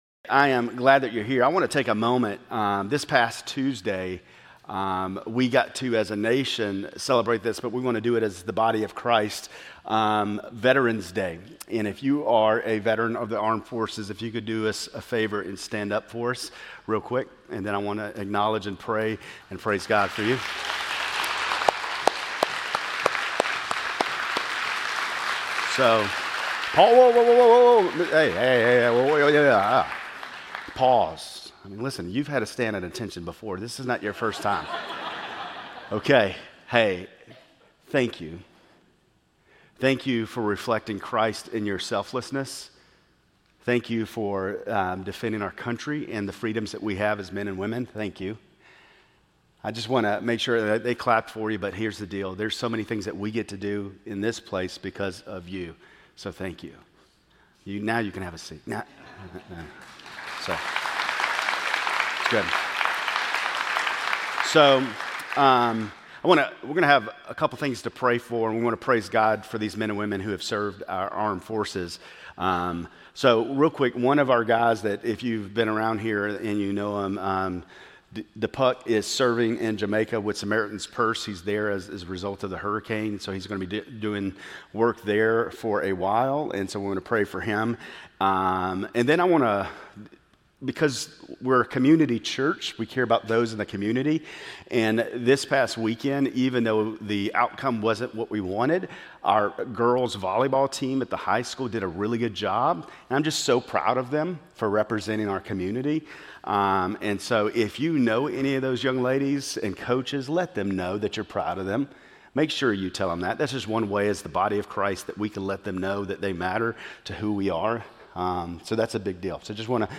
Grace Community Church Lindale Campus Sermons 11_16 Lindale Campus Nov 17 2025 | 00:31:54 1x 00:00 / 00:31:54 Subscribe Share RSS Feed Share Link Embed